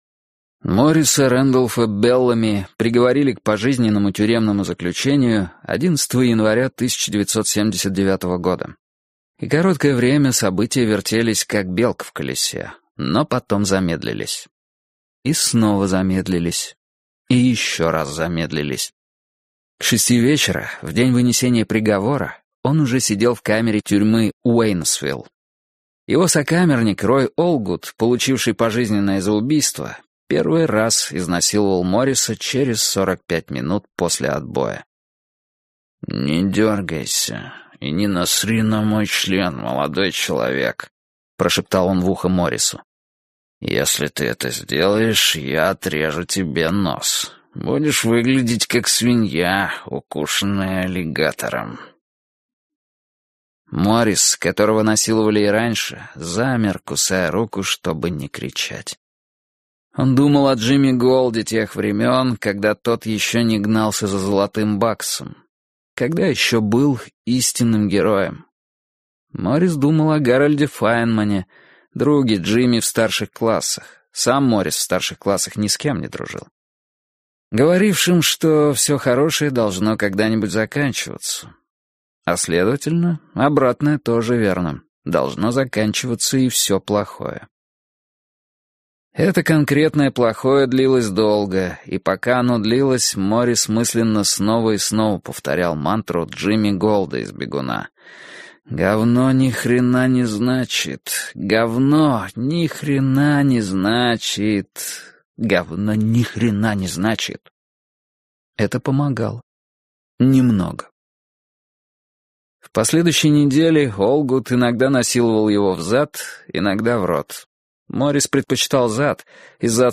Аудиокнига «Кто нашел, берет себе» в интернет-магазине КнигоПоиск ✅ Фэнтези в аудиоформате ✅ Скачать Кто нашел, берет себе в mp3 или слушать онлайн